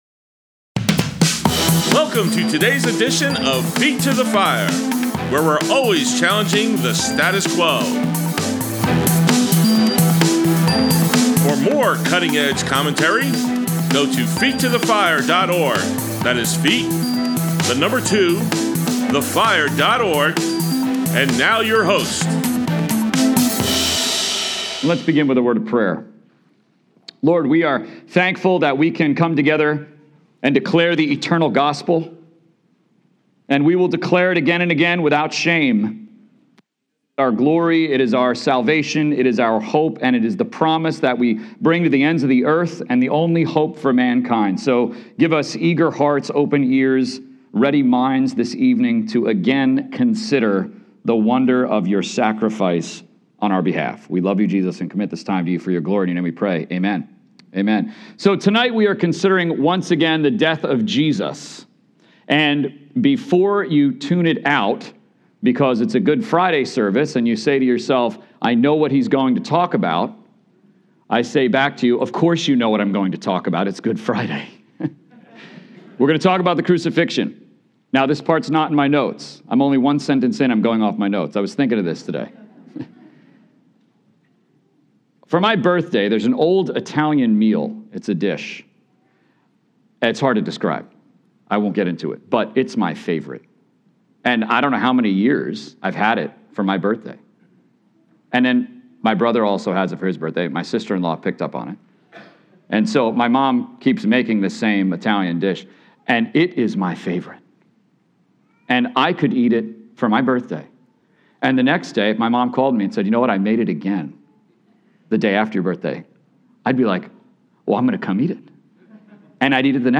Good Friday Sermon, Luke 9:18-22